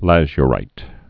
(lăzy-rīt, lăzə-, lăzhə-)